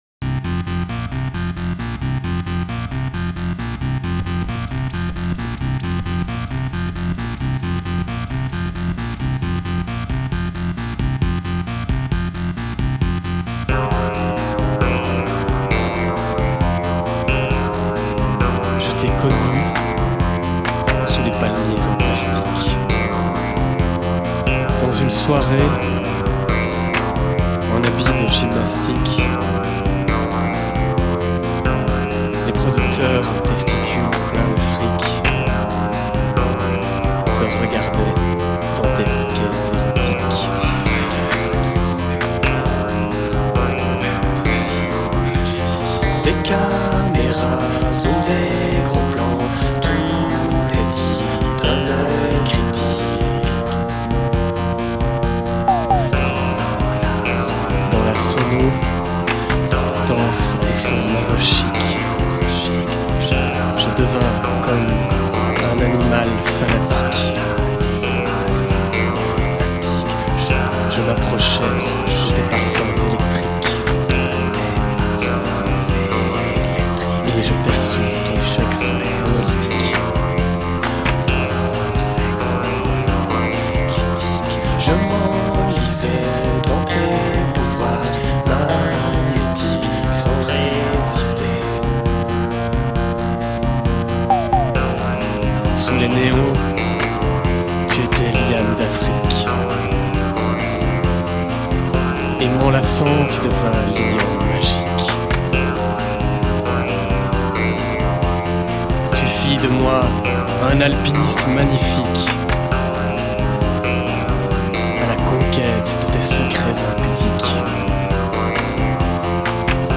This magical electrocollage must be absolutely downloaded.
This is a bastard cover, some sort of three-headed hydra.
We wanted to record a "yéyé" cover of the tune.
backing vocals